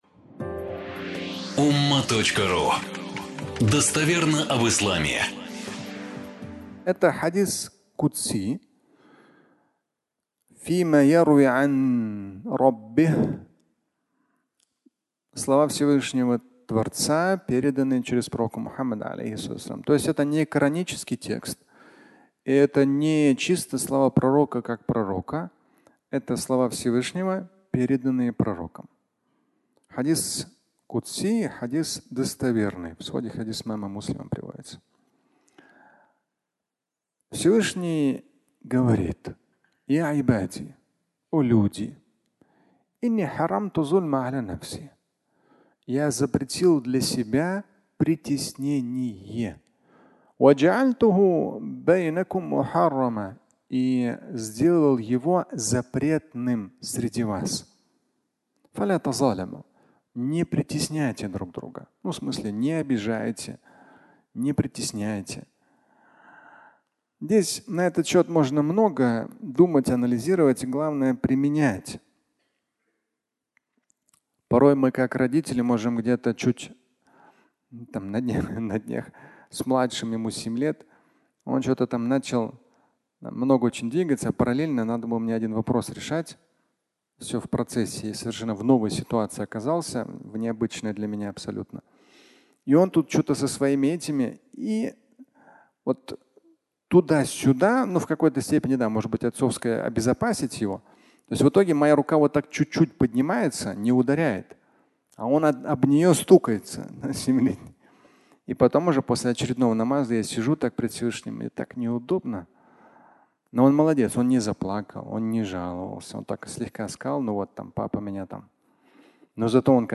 Проси – и будет тебе (аудиолекция)
Пятничная проповедь